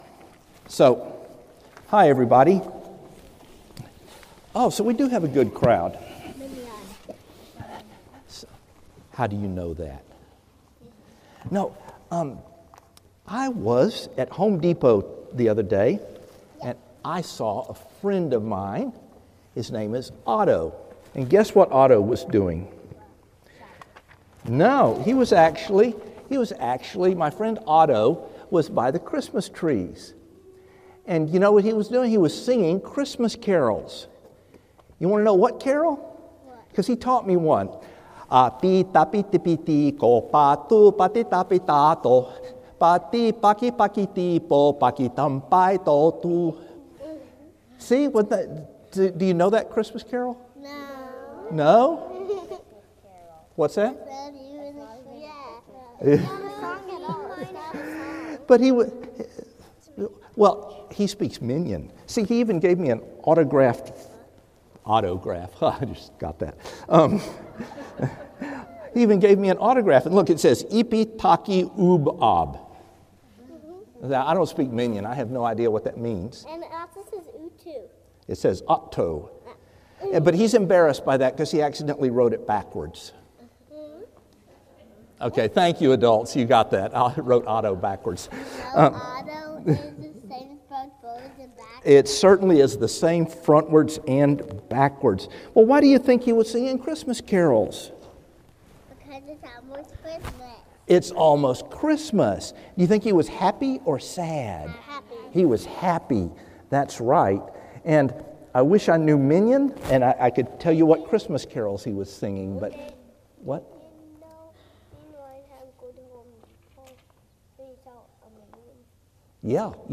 Sermons – Trinity Presbyterian Church